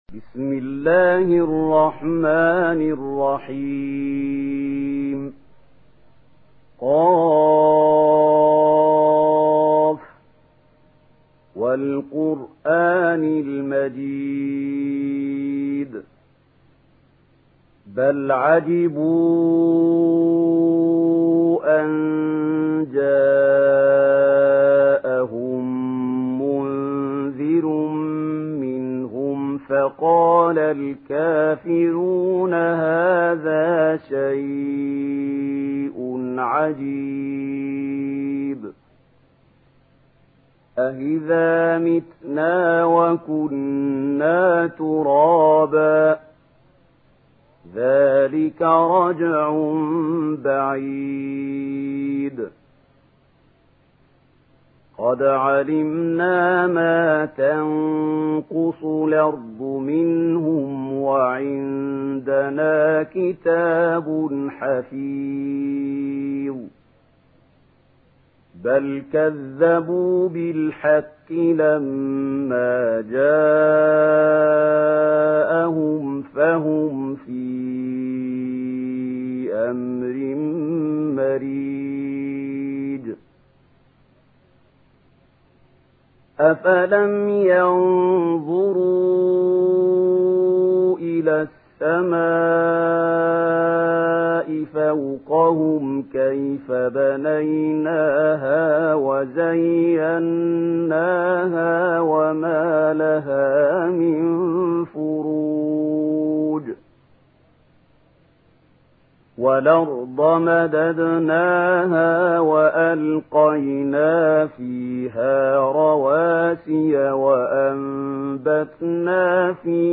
Une récitation touchante et belle des versets coraniques par la narration Warsh An Nafi.
Murattal